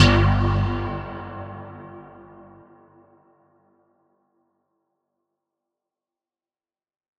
Bass_G_01.wav